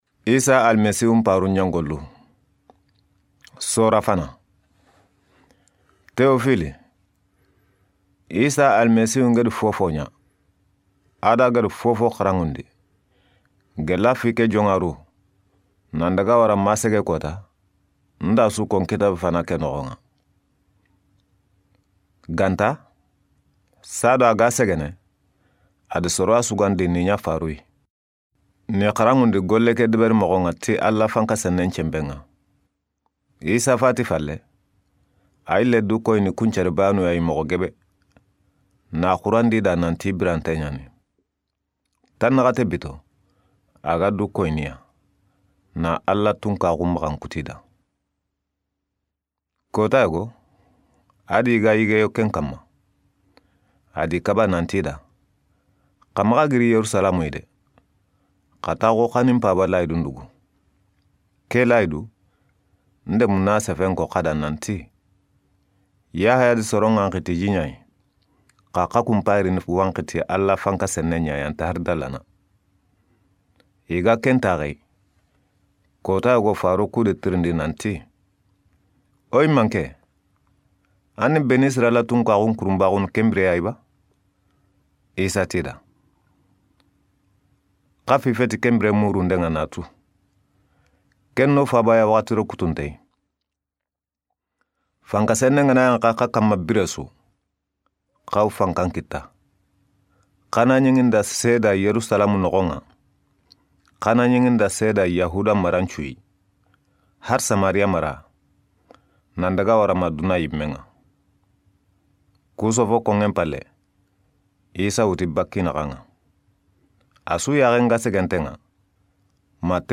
Acts of the Apostles is a book that tells us about the activities of the Apostles of the prophet Jesus. It begins with the assension of Jesus to heaven. These chapters are recorded in 'western' Soninke.